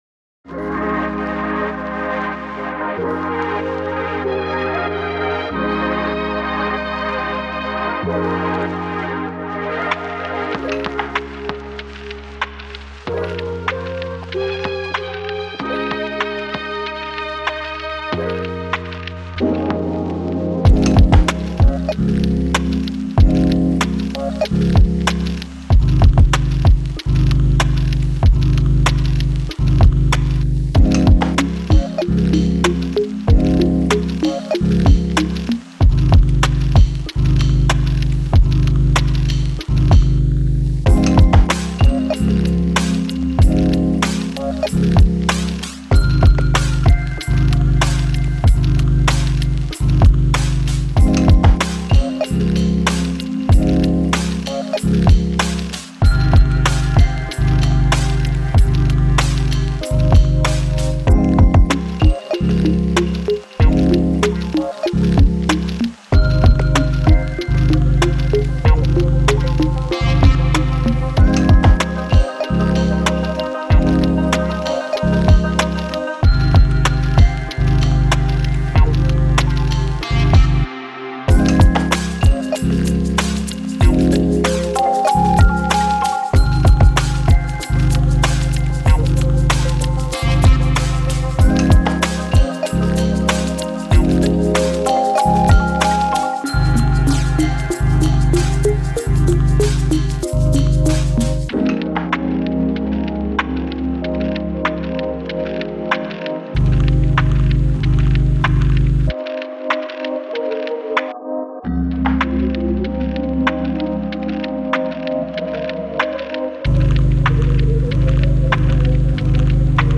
Всё мучаю Chill Hop , ищу свой звук.